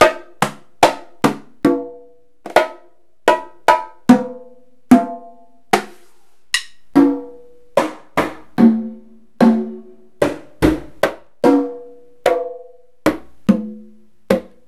Roland_S-550_Congas+Bongos.wav